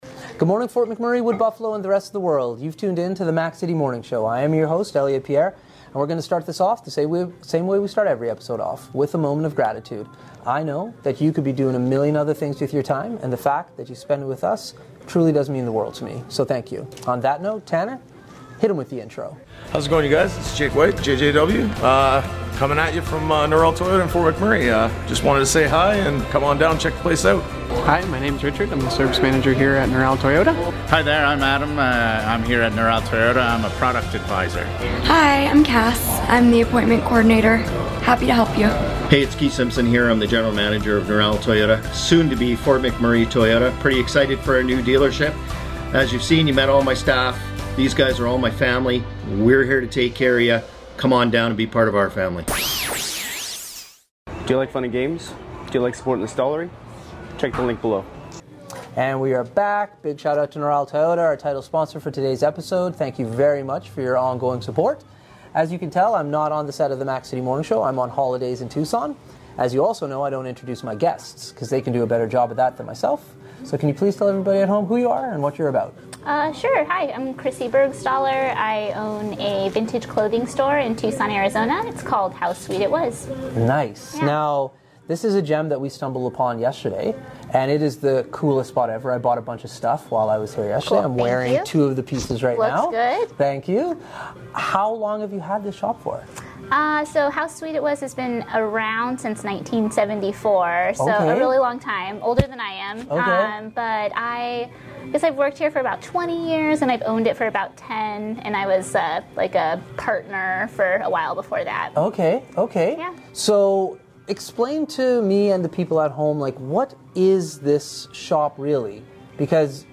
We are still down in Arizona